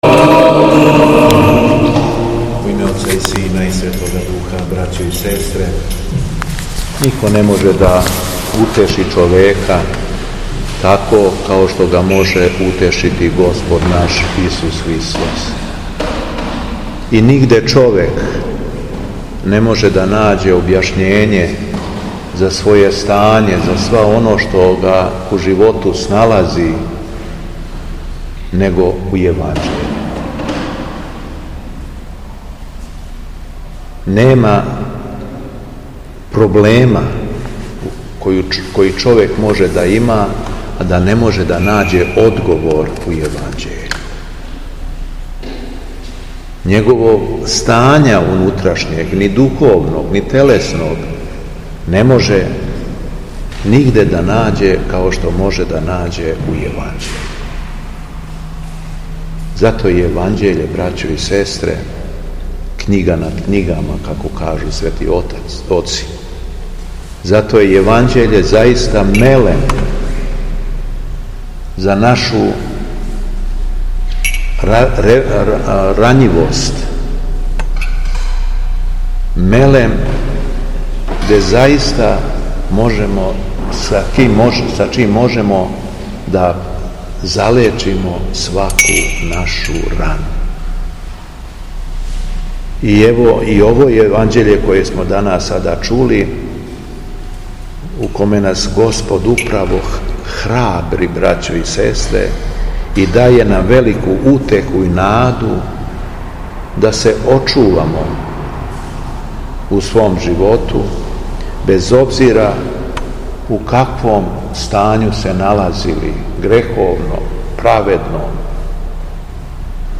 У петак, 14. јуна, када се наша Црква молитвено сећа Светог мученика Јустина Философа и Светог Јустина Ћелијског, Његово Преосвештенство Епископ шумадијски г. Јован служио је Свету Архијерејску Литургију у храму Свете Петке у крагујевачком насељу Виногради уз саслужење братства овога светога храма....
Беседа Његовог Преосвештенства Епископа шумадијског г. Јована
После прочитаног јеванђелског зачала, преосвећени владика се обратио беседом сабраном народу: